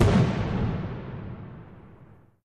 firework_distance_01.ogg